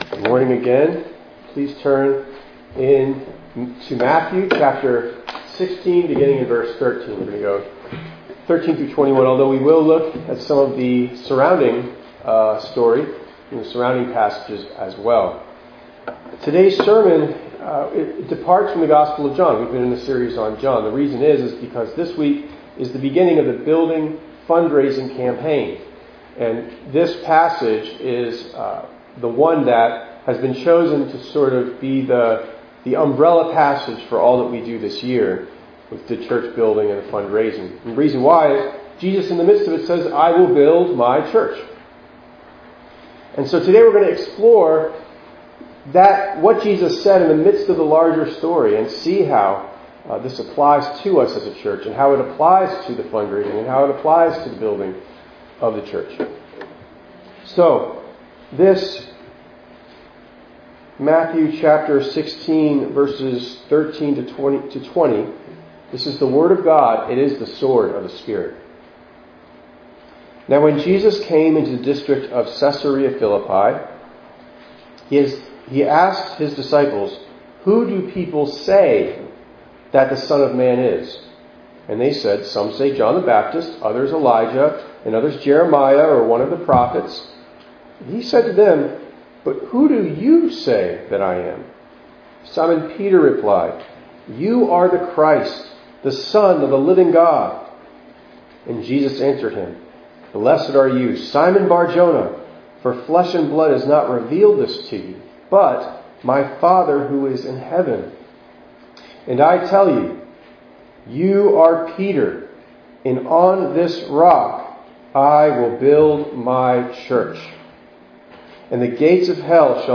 2_13_22_ENG_Sermon.mp3